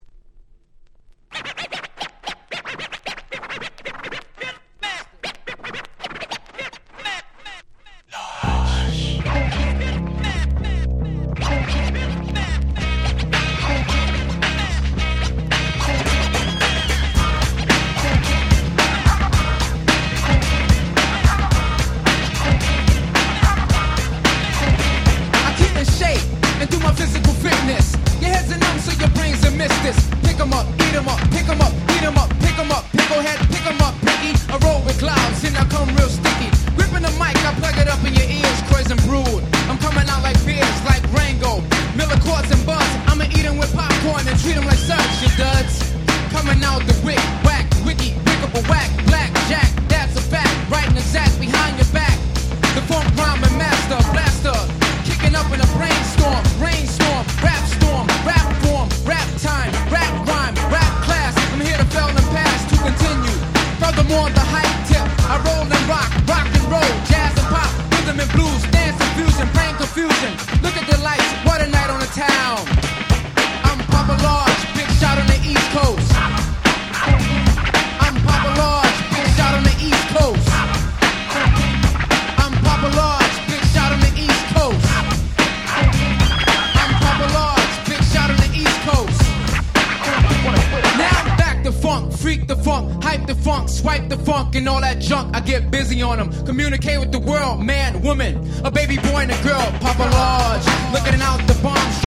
B-Boy大歓喜のEarly 90's Hip Hop Classics !!
もう男汁全開で手の付け様がございません。